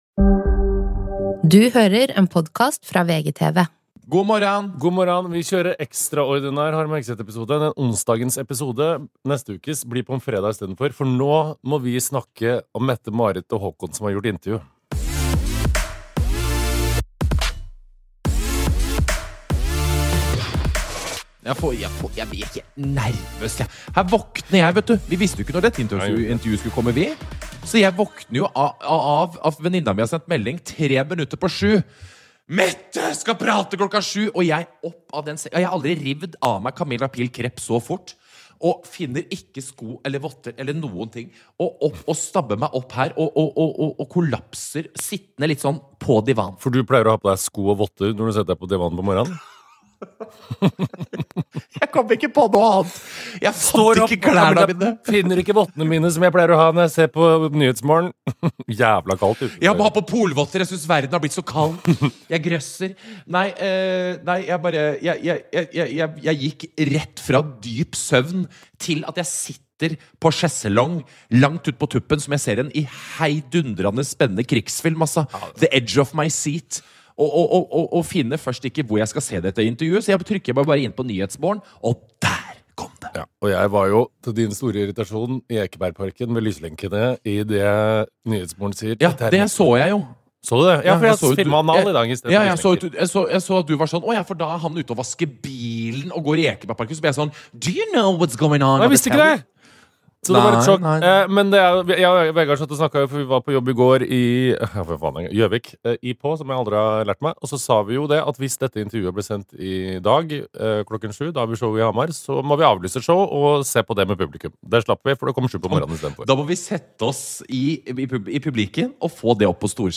Kronprinsesse Mette-Marit og Kronprins Haakon har gitt et intervju til NRK om Epstein-kontakten og vi har raska oss sammen, inn i studio og foran mikrofon i Stokke.